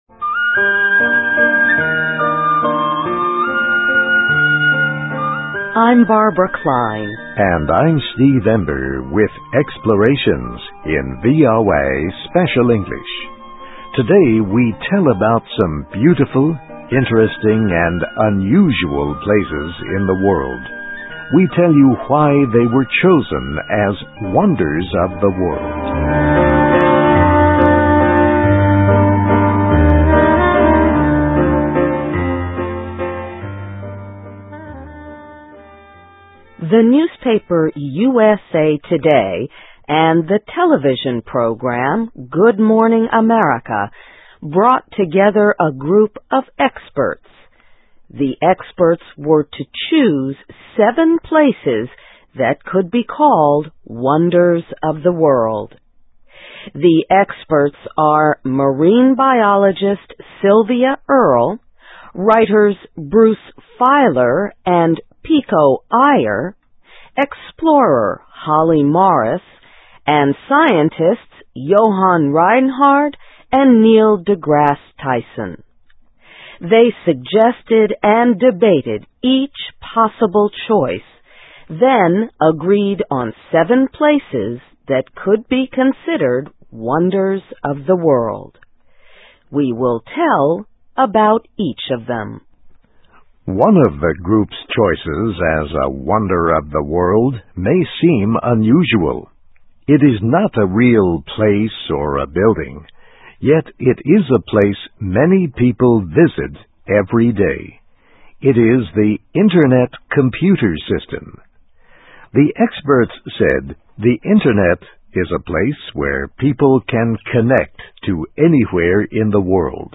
Places: A New List of Wonders, From the Maya Pyramids to the Net (VOA Special English 2006-12-12)
Listen and Read Along - Text with Audio - For ESL Students - For Learning English